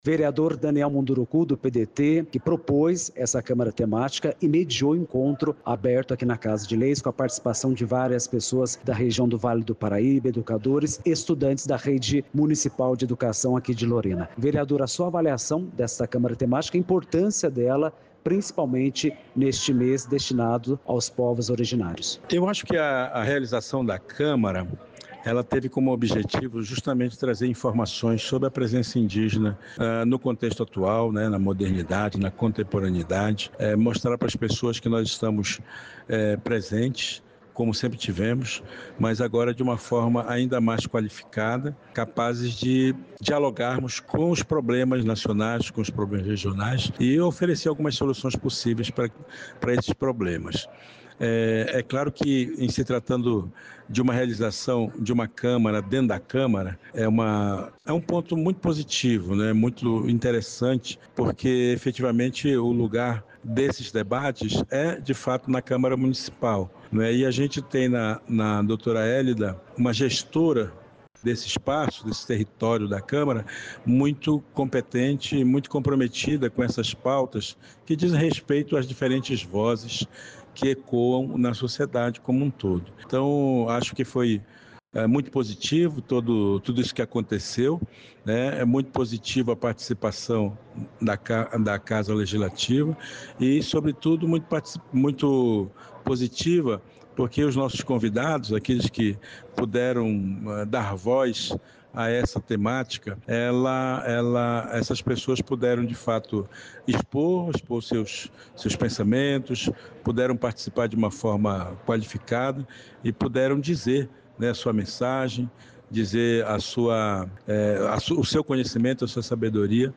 Entrevistas (áudios):